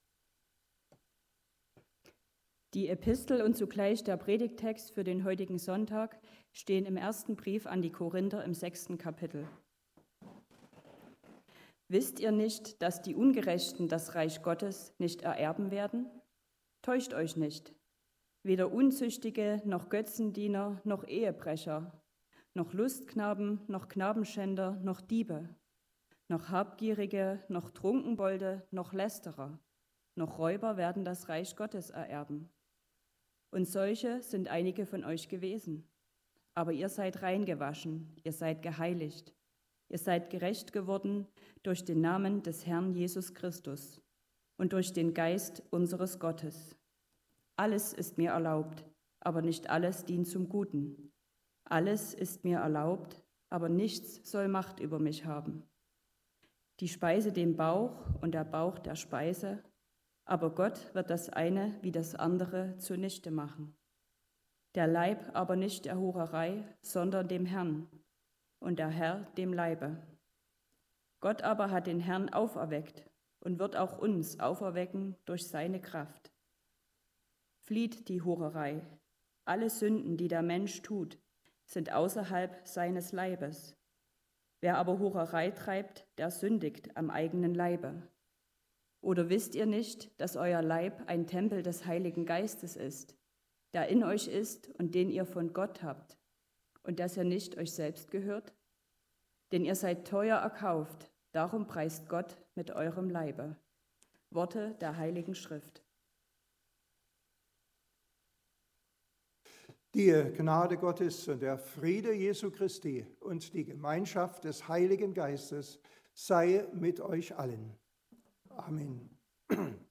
Korinther 6, 9-20 Gottesdienstart: Predigtgottesdienst Obercrinitz Was bedeutet ein Leben mit Jesus?